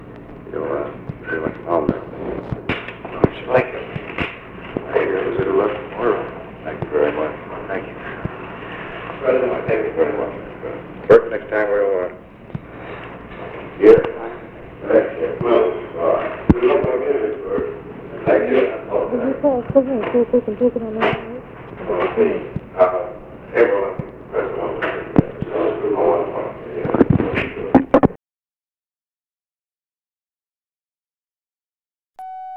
OFFICE CONVERSATION, February 11, 1964
Secret White House Tapes | Lyndon B. Johnson Presidency